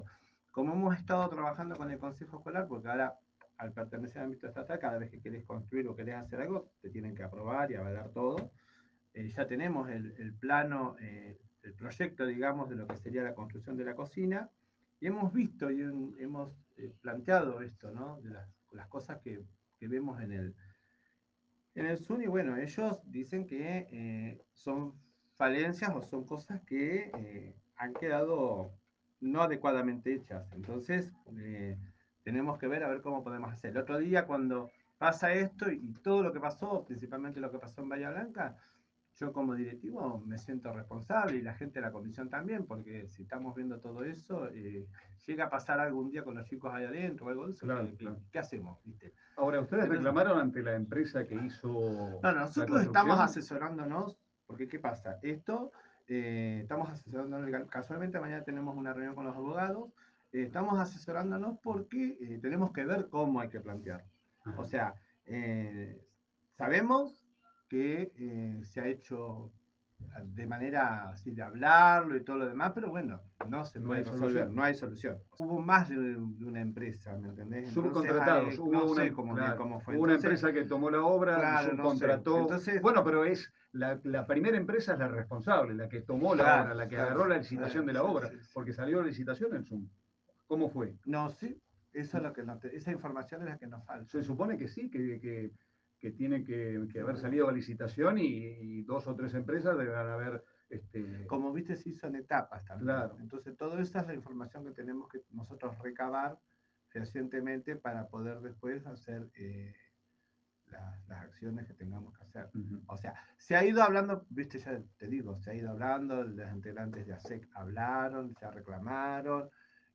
En diálogo con Radio Claromecó 106.9